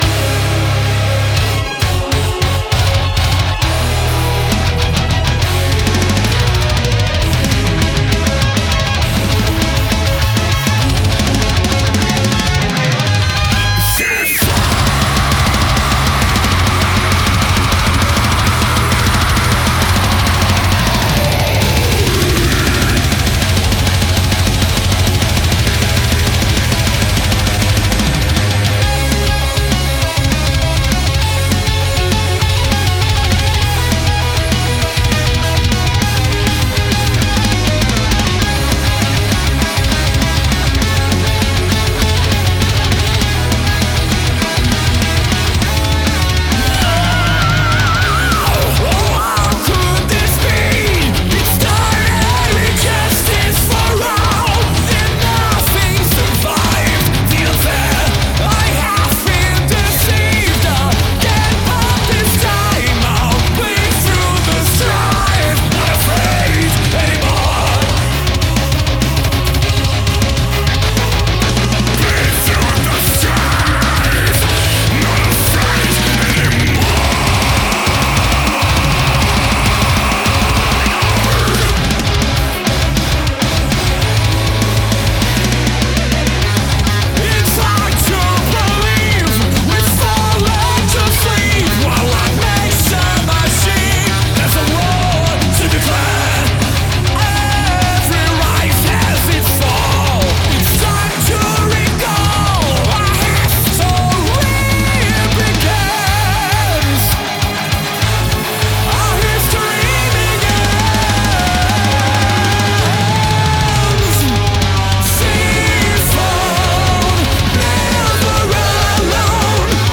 Жанр: Death Metal Страна: Sweden